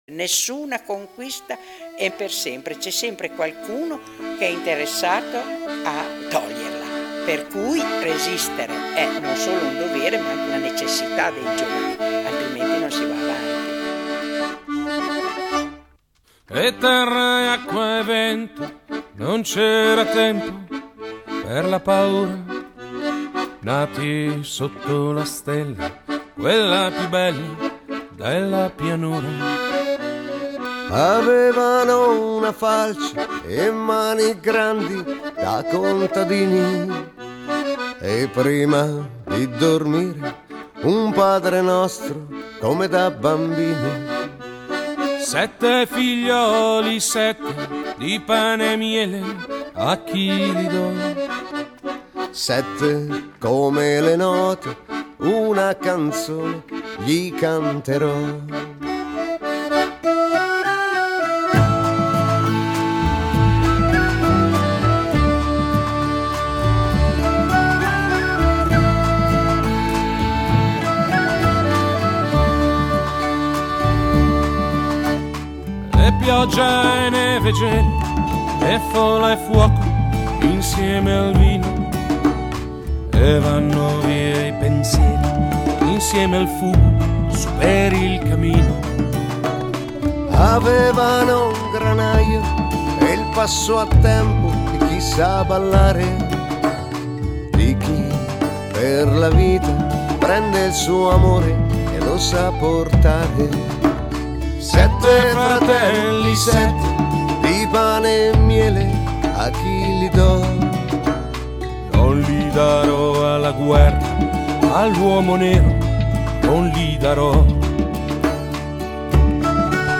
Mi ha regalato una pausa di serenità.
Sembra quasi una ninna nanna piena di colore e folklore